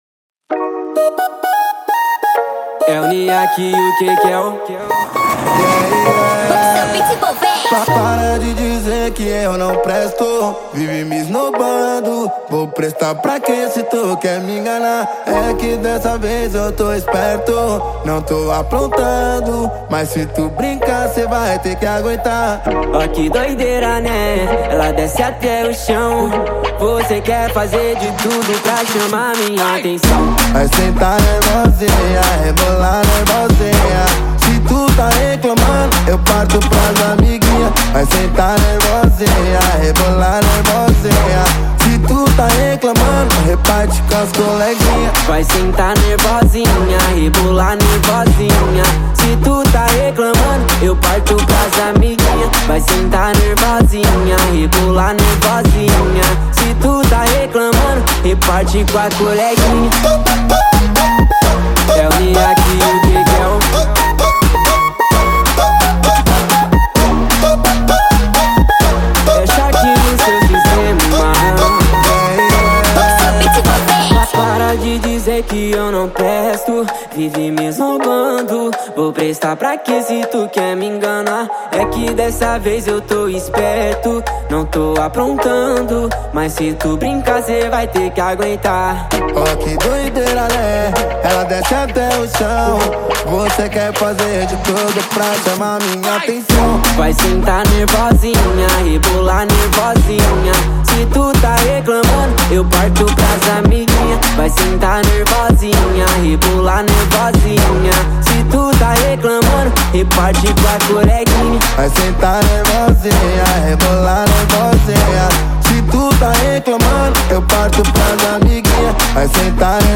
Baixar Funk Letras